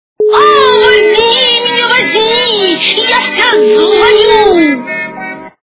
» Звуки » Смешные » Женский голос с мобилки - О, возьми меня, возьми. Я вся звоню...
При прослушивании Женский голос с мобилки - О, возьми меня, возьми. Я вся звоню... качество понижено и присутствуют гудки.